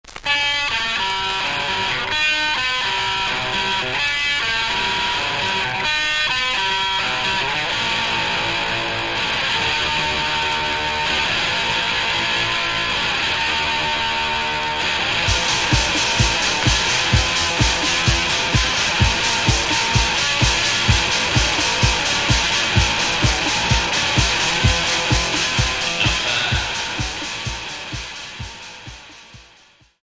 Punk industriel Premier 45t retour à l'accueil